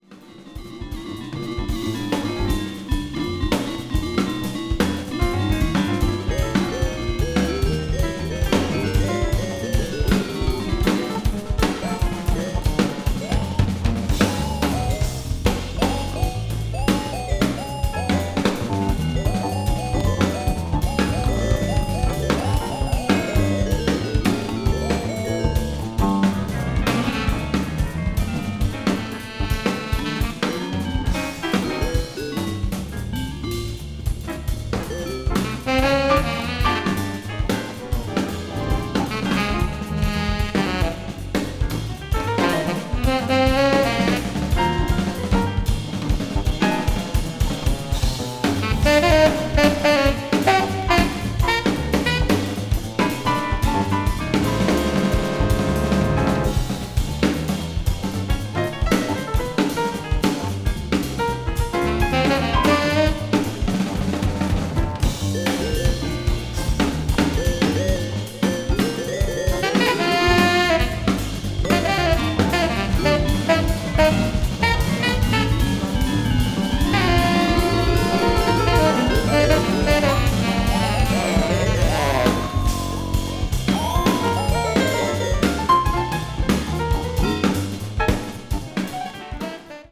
avant-jazz   contemporary jazz   crossover